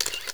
camera_shutter.wav